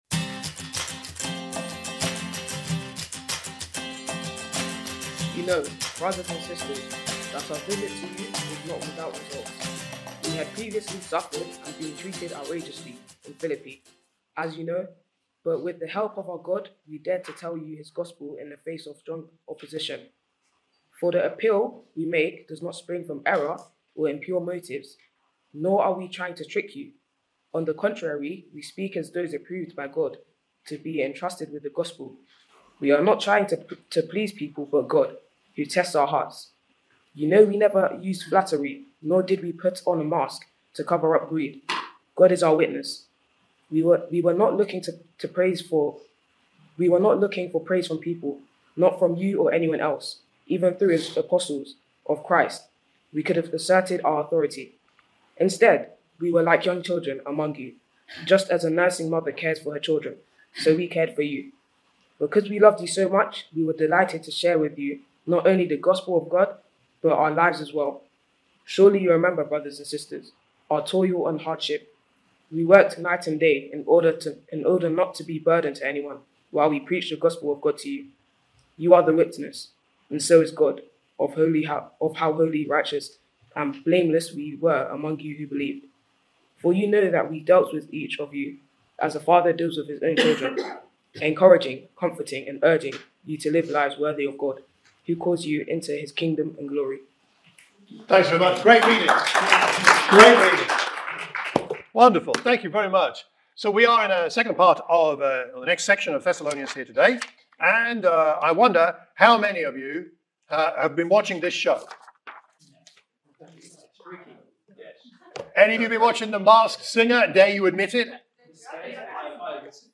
A sermon for the Watford church of Christ